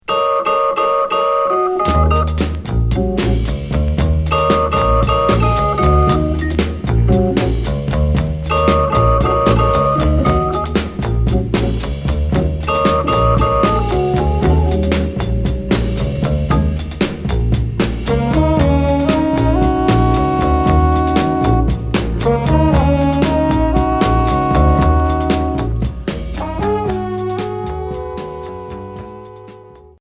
Groove easy listening